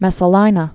(mĕsə-līnə), Valeria Died AD 48.